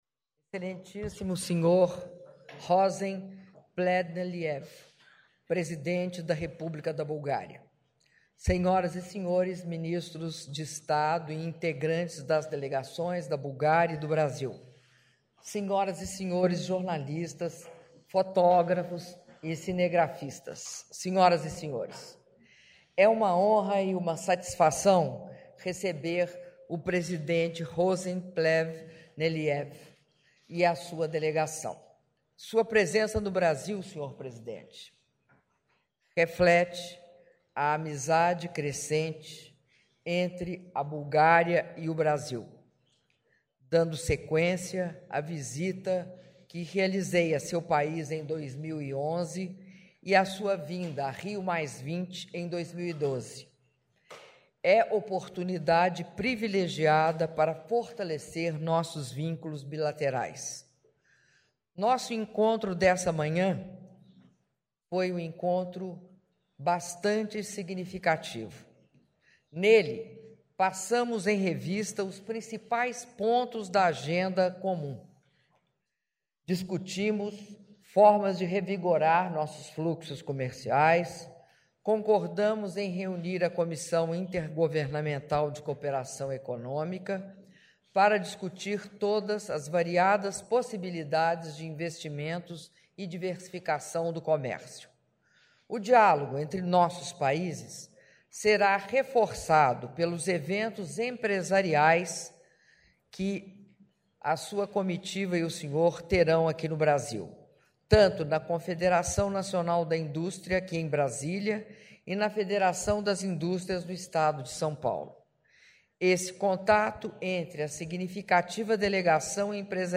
Áudio da declaração à imprensa da Presidenta da República, Dilma Rousseff, após cerimônia de assinatura de atos - Brasília/DF (6min12s)